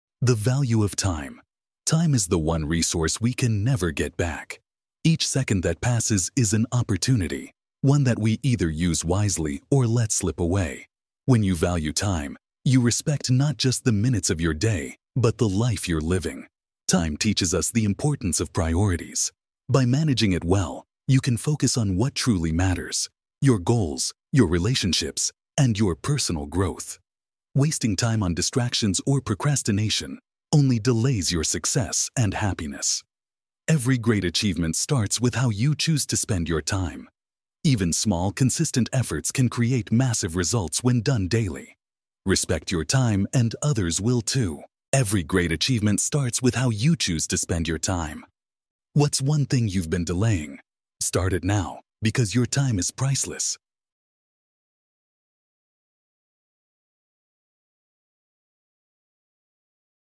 daily english practice by reading and listening to short stories.